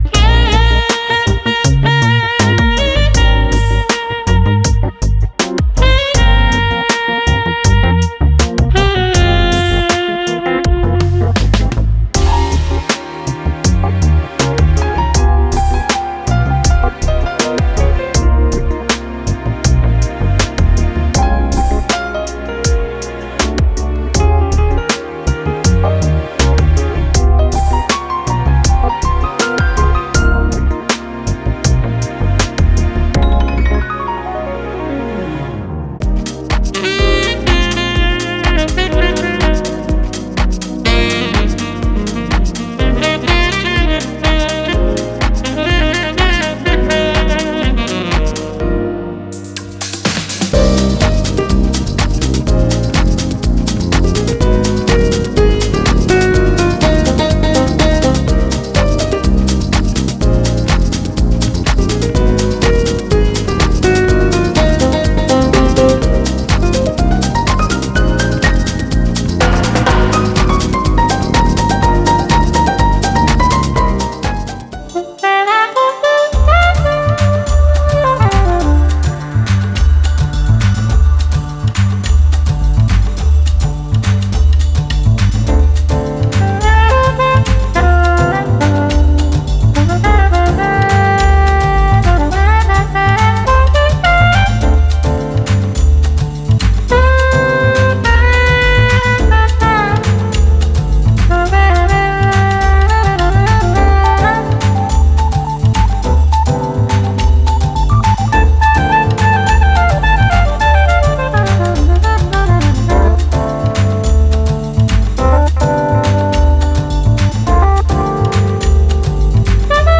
Lounge Jazz